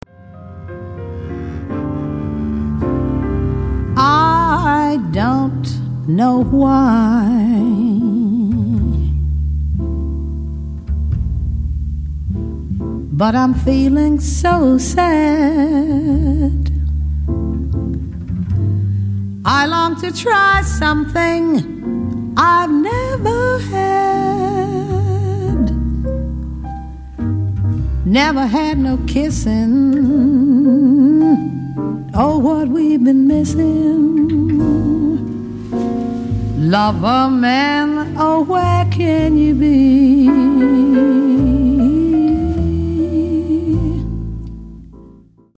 piano
cornet
tenor saxophone